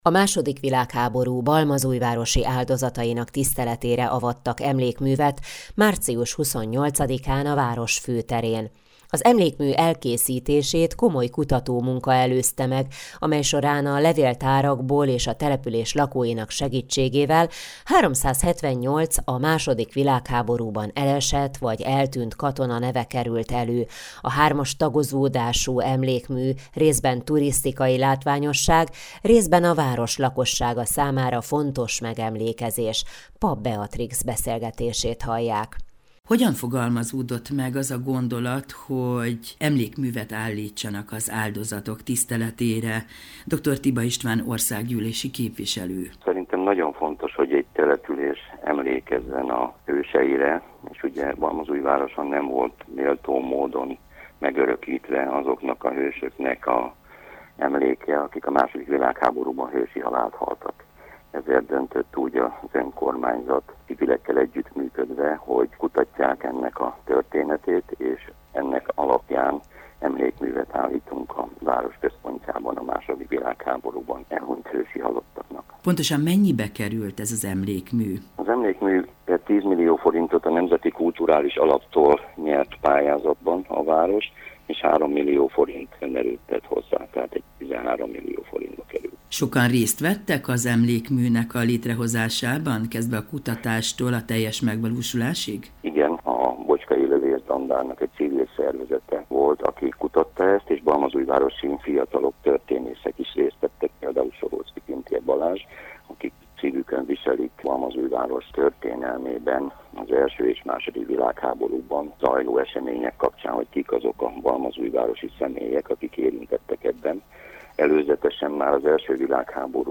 készített összeállítást az Európa Rádió ban: